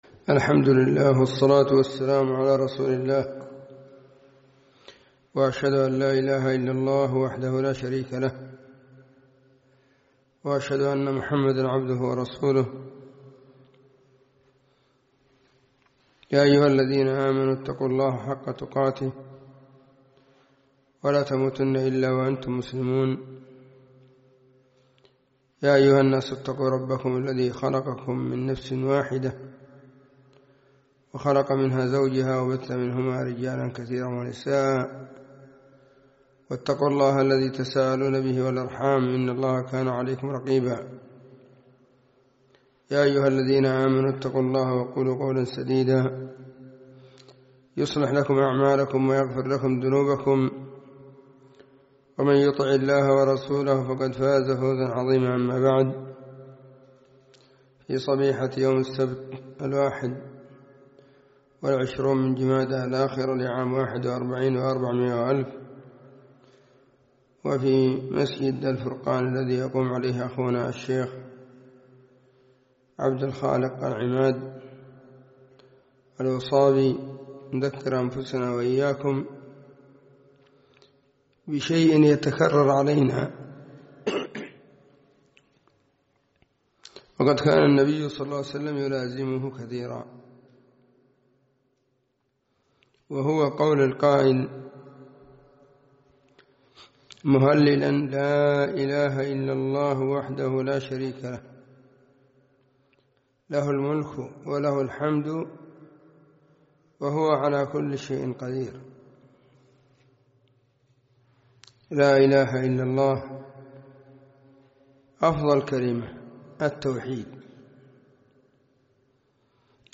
السبت 21 جمادى الآخرة 1441 هــــ | كلمــــات | شارك بتعليقك
ألقيت – فجر- يوم السبت – في -مركز الفرقان- بقشن – المهرة –